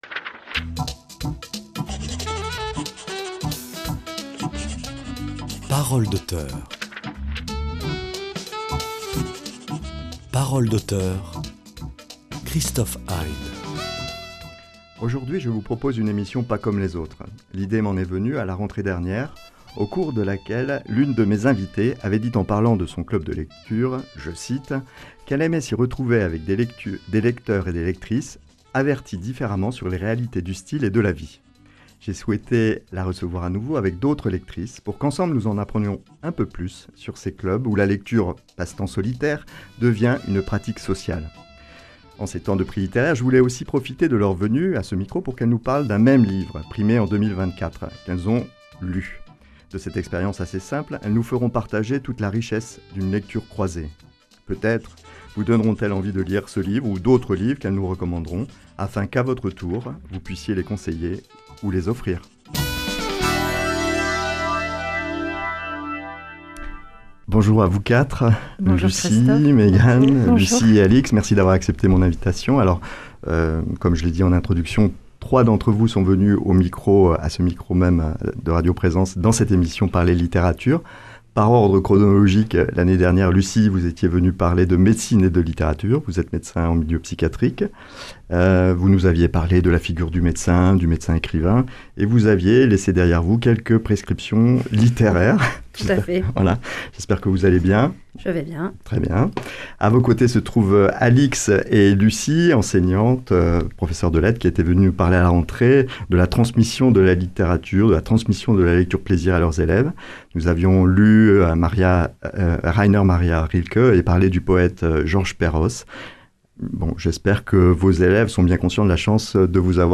En ces temps de prix littéraires, j’ai invité des lectrices pour nous parler d’un même livre qu’elles auraient lu. De cette expérience assez simple, elles nous feront partager toute la richesse des lectures possibles et peut-être nous donneront elles envie modestement de lire ce livre ou d’autres, qu’à notre tour nous pourrons conseiller ou offrir pour les fêtes.